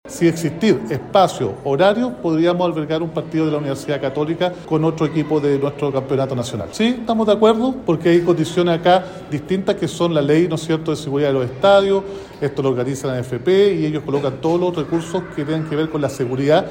A pesar de esta preocupación, desde la Municipalidad de Temuco reconocieron acercamientos y que existe disponibilidad para albergar este partido, tal como lo indicó el alcalde Roberto Neira.